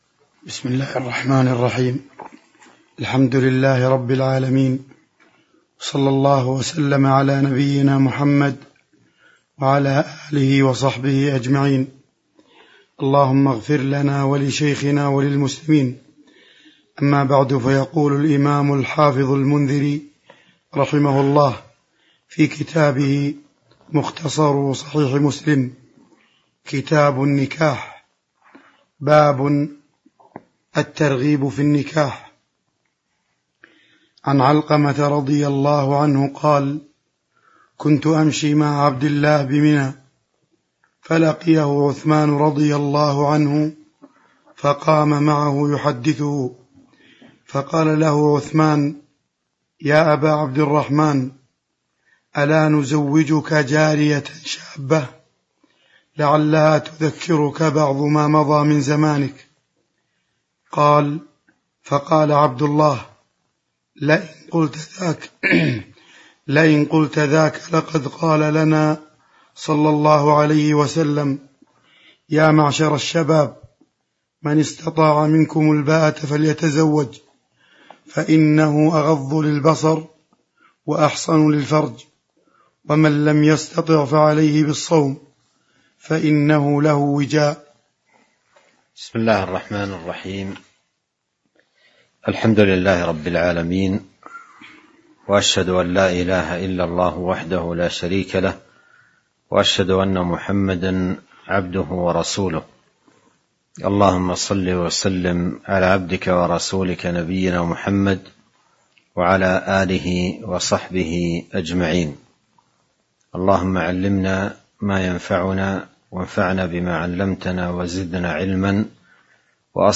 تاريخ النشر ٢٣ ذو الحجة ١٤٤٢ هـ المكان: المسجد النبوي الشيخ